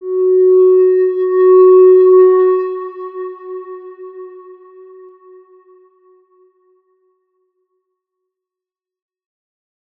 X_Windwistle-F#3-mf.wav